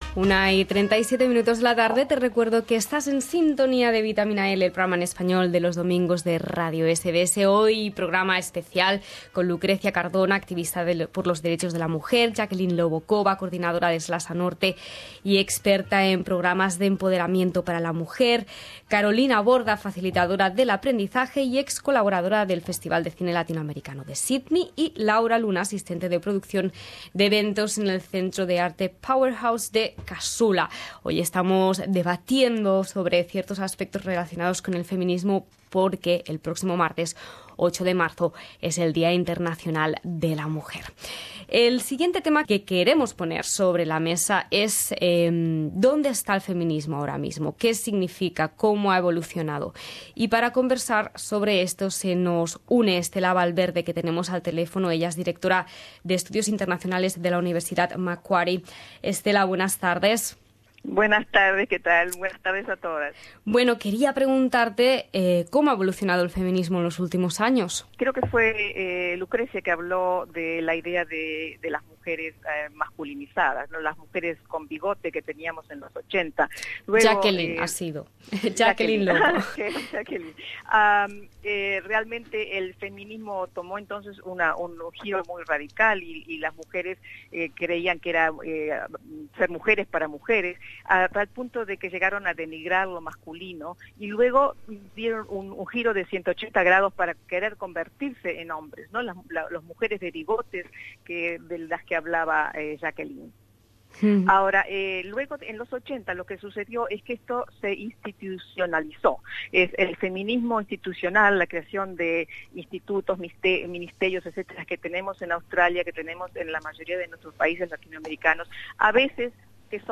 Juntamos en los estudios a cinco mujeres destacadas de nuestra comunidad para debatir diversos temas relacionados con el feminismo.